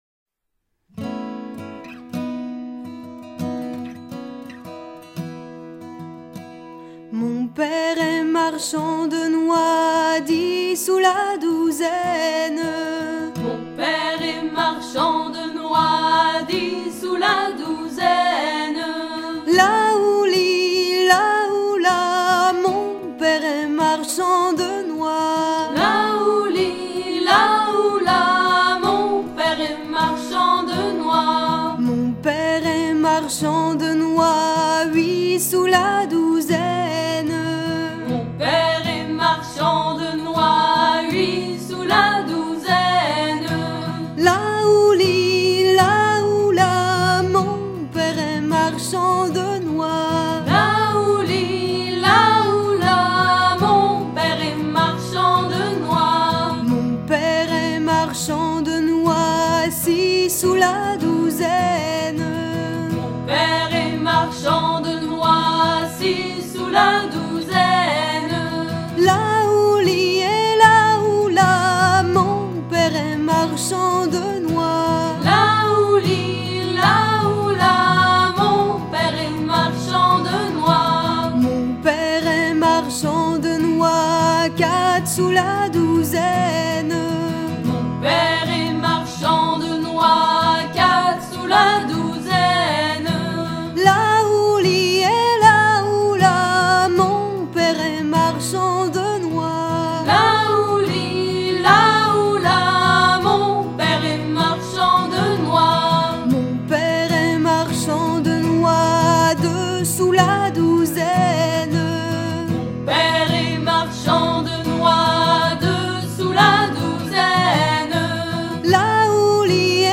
à virer au cabestan
Genre énumérative
Pièce musicale éditée